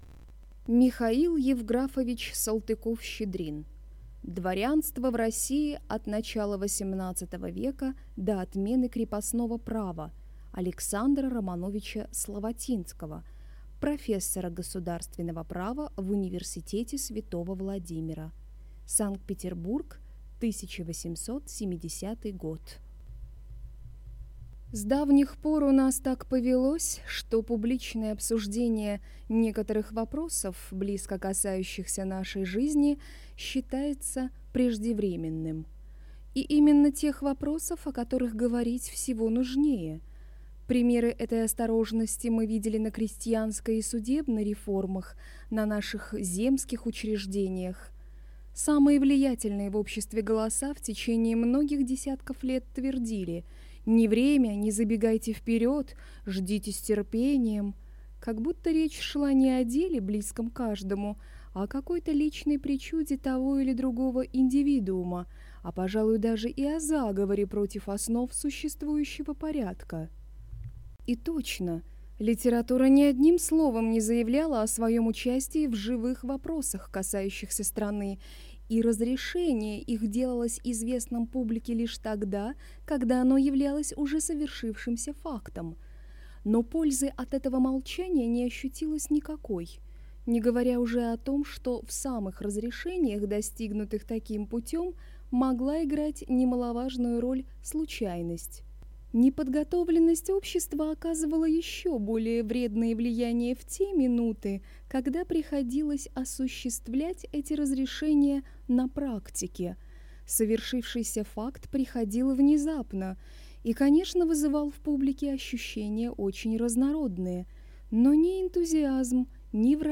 Аудиокнига Дворянство в России от начала XVIII века до отмены крепостного права | Библиотека аудиокниг